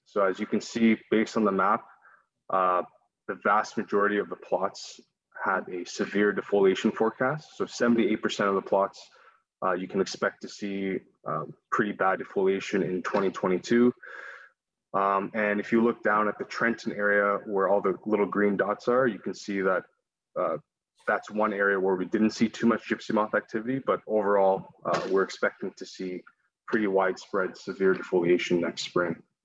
At Monday’s Quinte West council meeting, council heard the results of a detailed study conducted by BioForest of the damage projected to be caused by the moths in 2022.